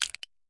Soda » soda can crunch 04
描述：我手里拿着一罐空罐子。 用Tascam DR40录制。